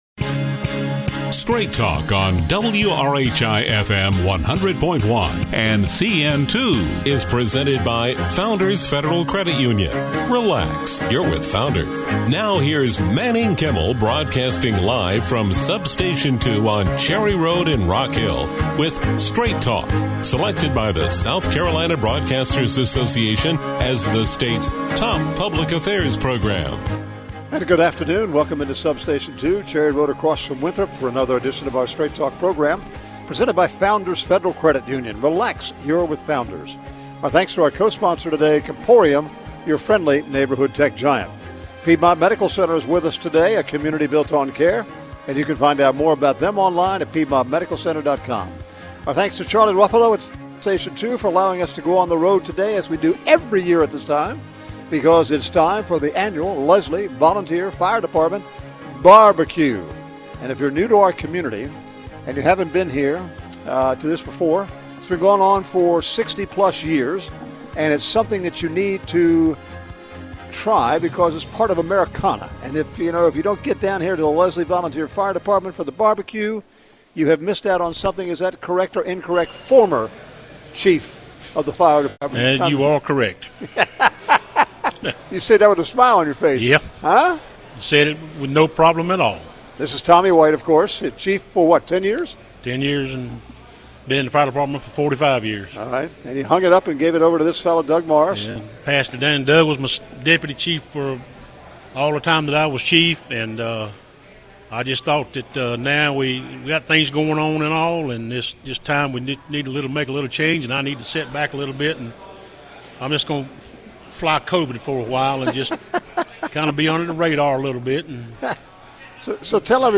Live From the Lesslie Fire Department Annual BBQ.